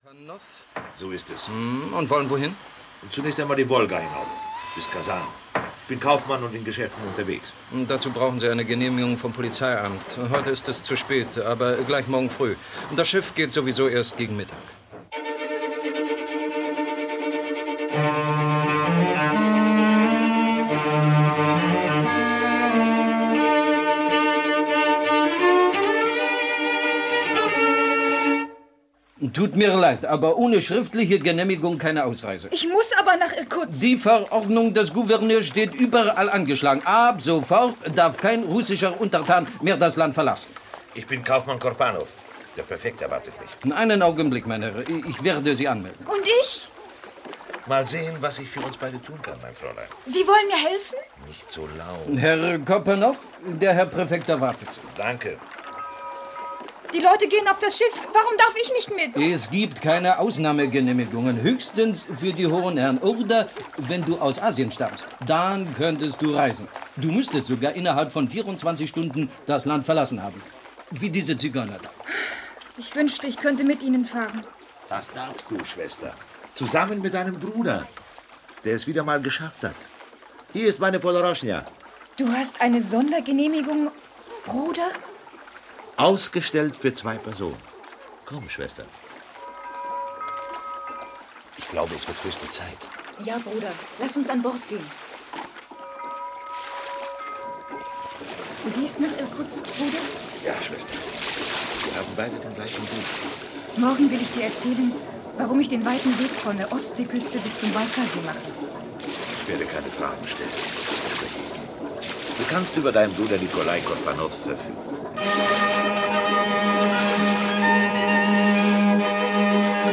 Der Kurier des Zaren - Jules Verne. - Hörbuch